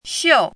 chinese-voice - 汉字语音库
xiu4.mp3